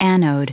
Prononciation du mot anode en anglais (fichier audio)
Prononciation du mot : anode